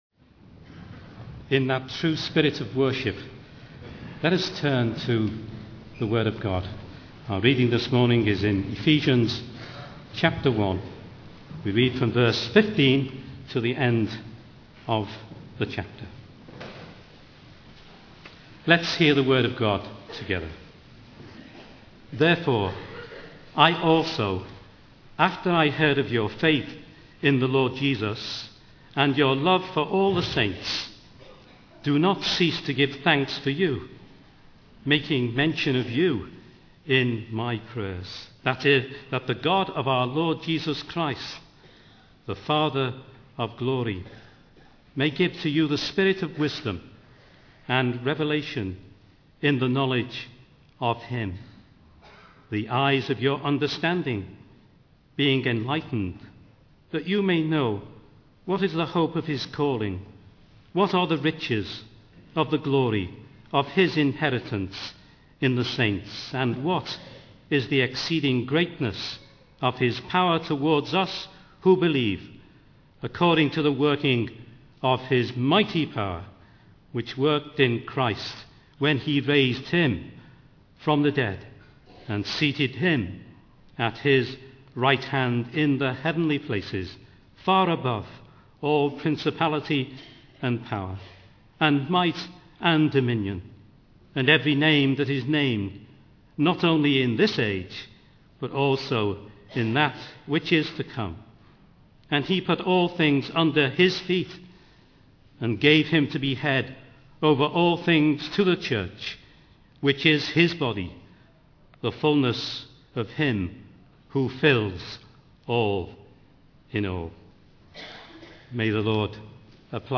In this sermon, the speaker emphasizes the importance of discipline in the Christian life. He mentions the examples of George Whitfield and John Wesley, who divided their days into specific time slots for sleep, work, prayer, and Bible reading.